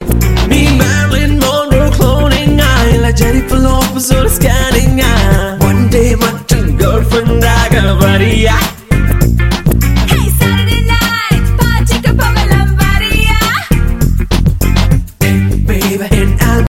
TAMIL | AFRO BEATS | RNB | OLDSCHOOL